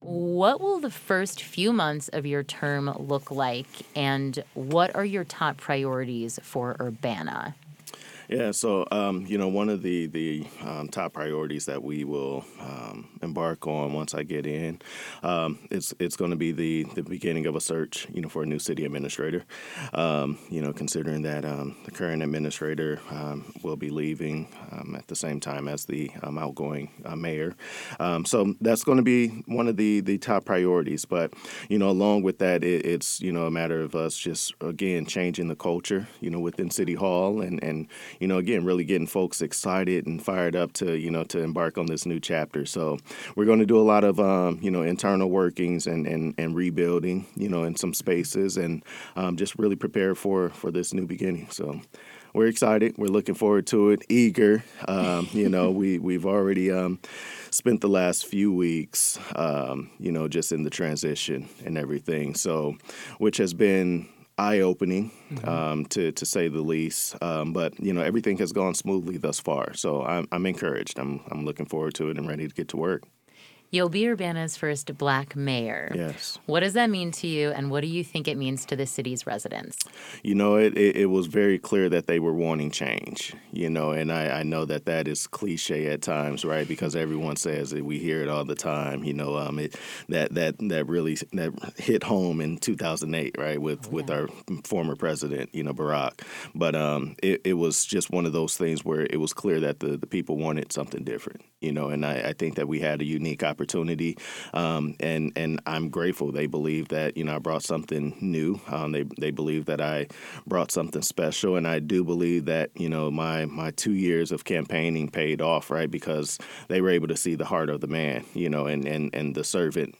This interview has been edited for conciseness and clarity. https